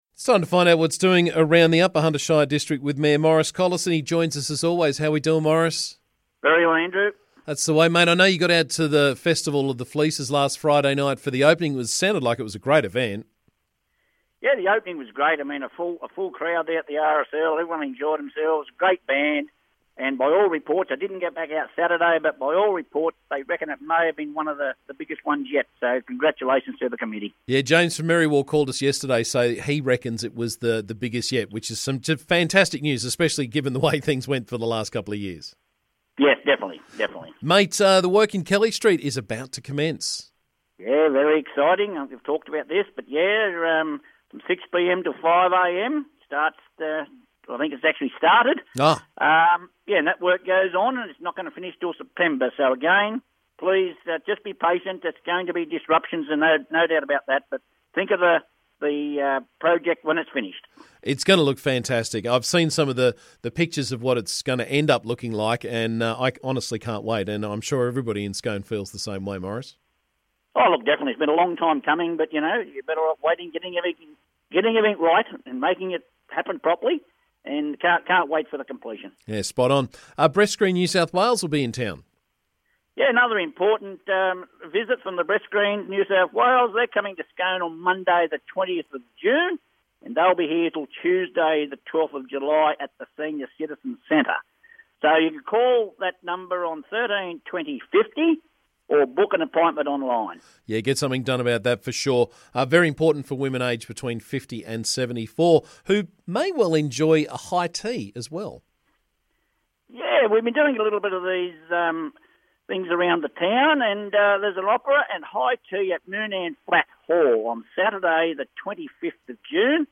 UHSC Mayor Maurice Collison was on the show this morning to keep us up to date with what's doing around the district.